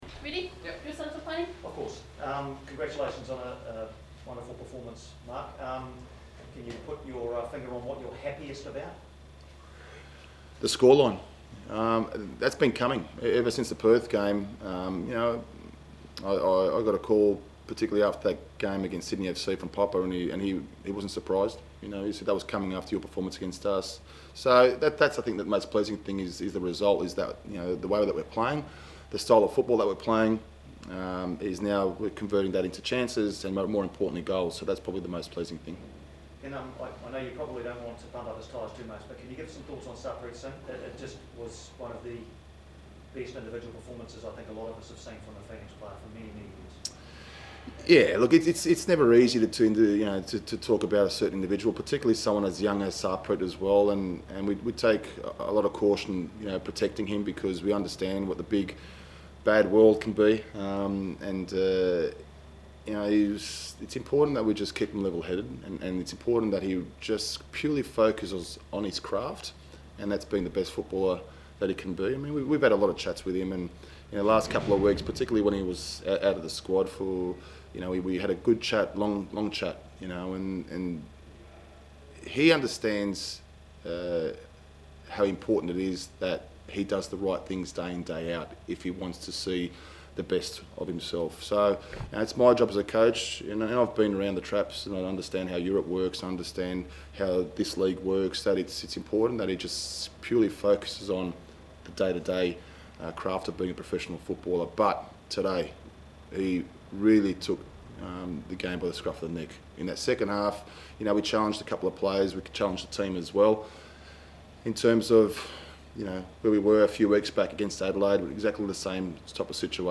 Phoenix 4 Roar 1. The Press conference
Mark Rudan interviewed after tonight’s showing from the Wellington Phoenix.